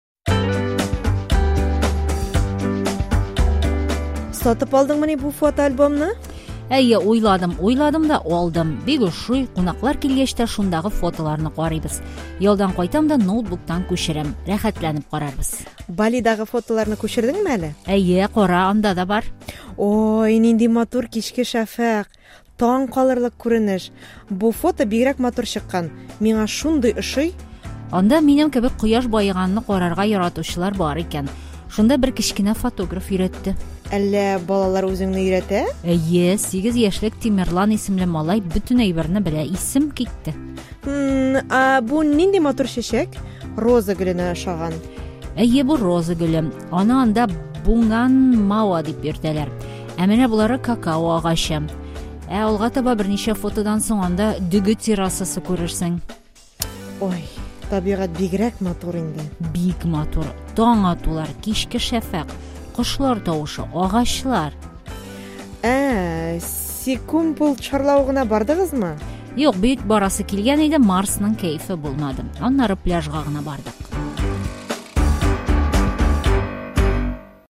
ДИАЛОГ: МАТУРЛЫК!